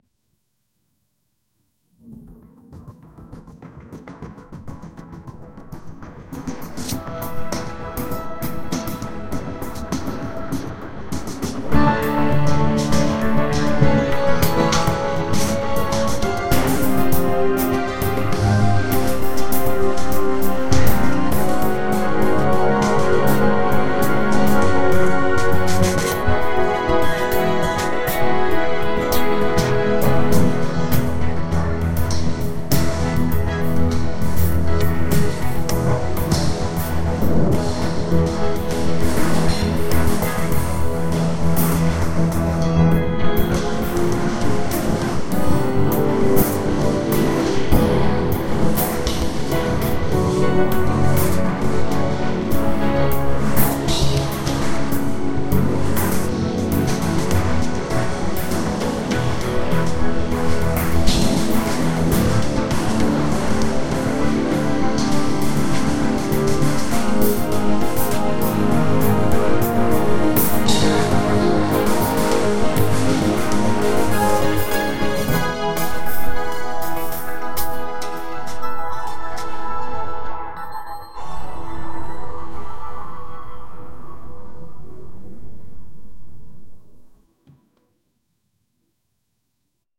リズミカル
情熱的